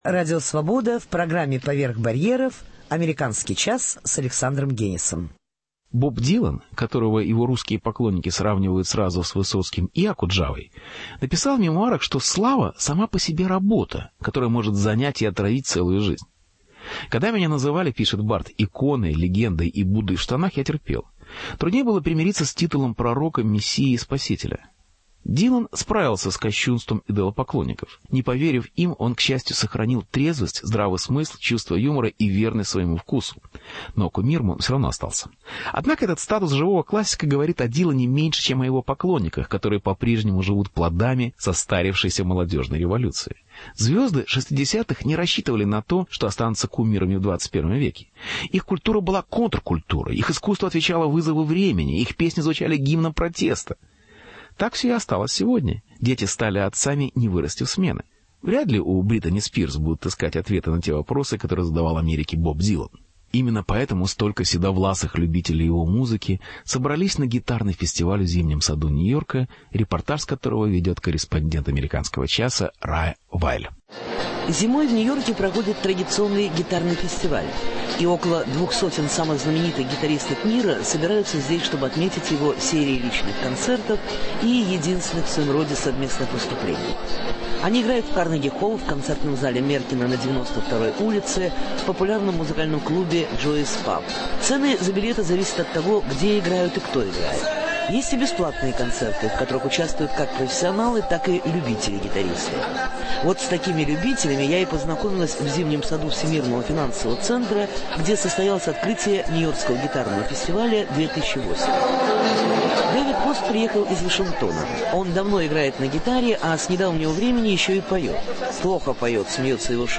По следам Боба Дилана. Репортаж